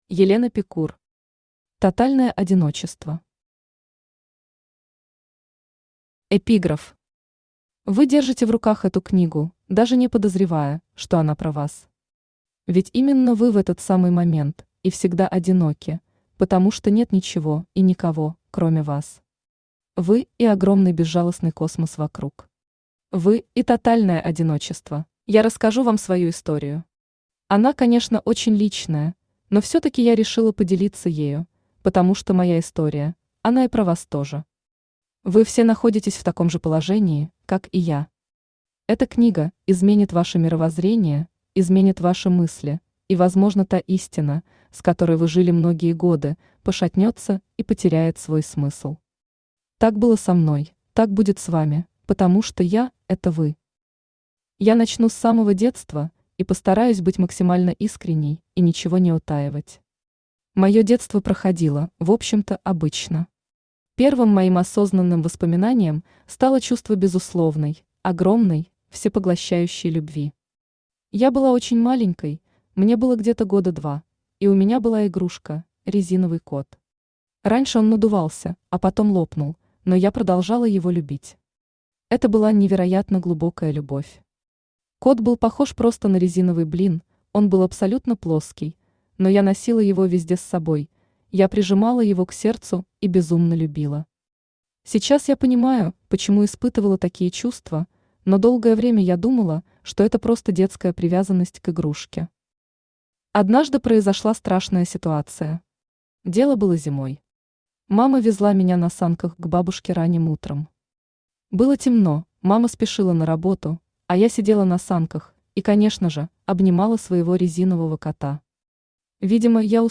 Аудиокнига Тотальное одиночество | Библиотека аудиокниг
Aудиокнига Тотальное одиночество Автор Елена Пикур Читает аудиокнигу Авточтец ЛитРес.